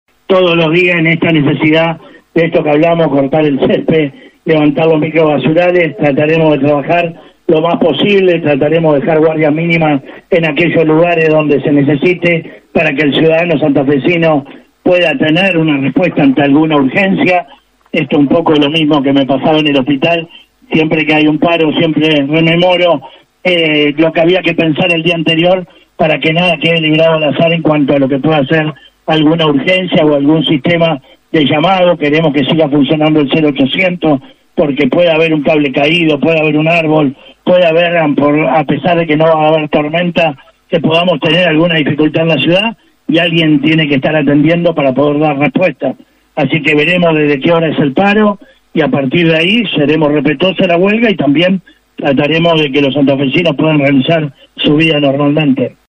Escucha la palabra de Juan Pablo Poletti en Radio EME:
JUAN-PABLO-POLETTI-INT-SANTA-FE-SOBRE-PARO-ASOEM-24-DE-ENERO.mp3